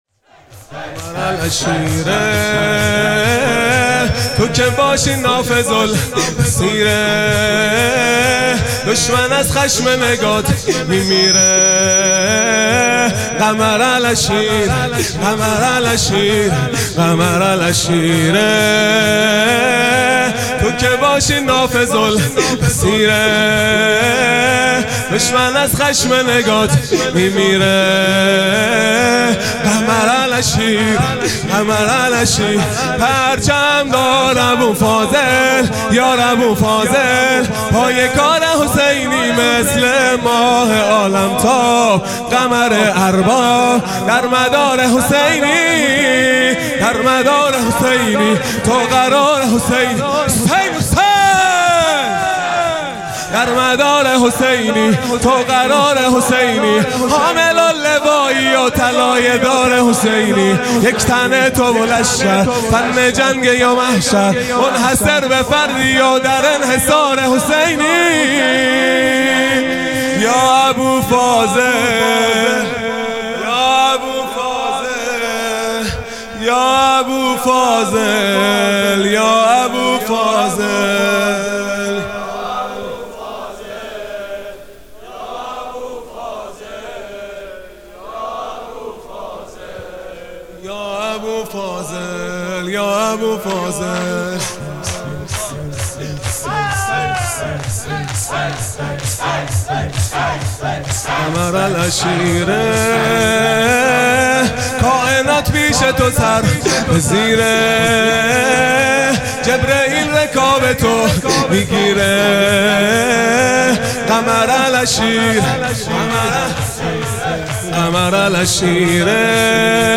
شور | قمر العشیره تو که باشی نافذ البصیره | ۵ مرداد ماه ۱۴۰۲
محرم الحرام ۱۴۴5 | شب عاشورا | پنجشنبه 5 مرداد ماه ۱۴۰2